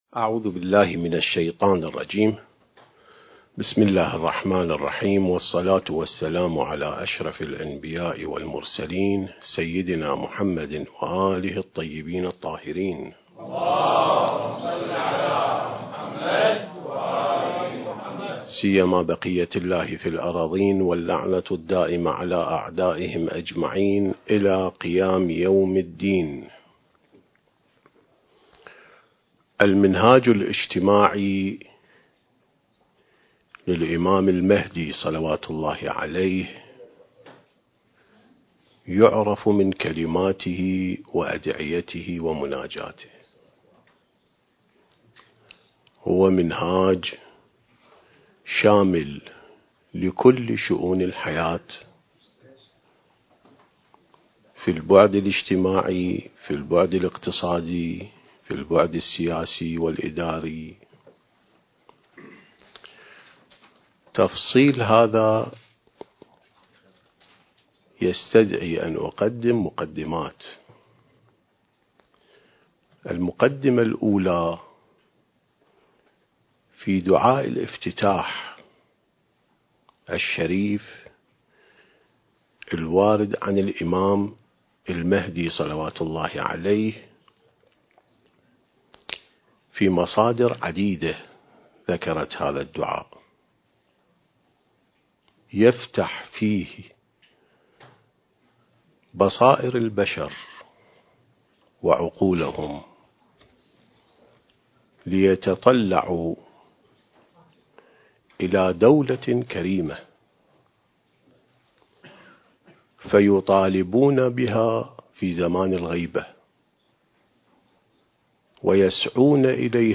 سلسة محاضرات الإعداد للمهدي (عجّل الله فرجه) (8) التاريخ: 1444 للهجرة